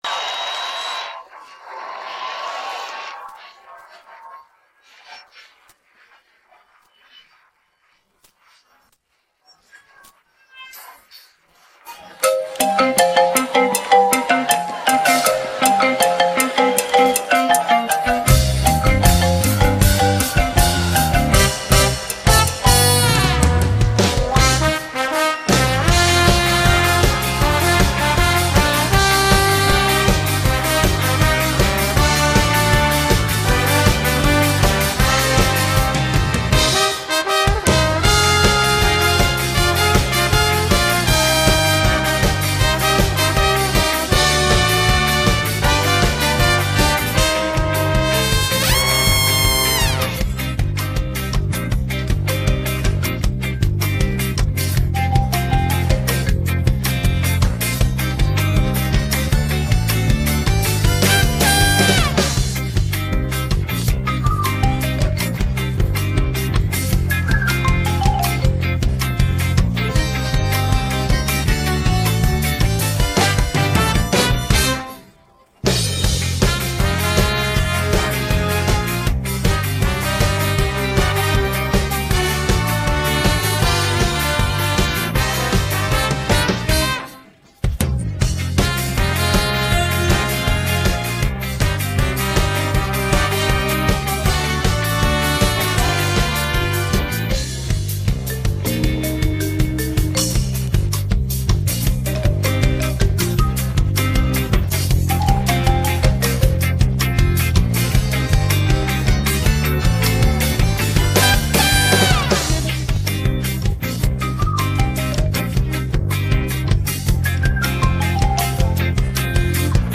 караоке мінус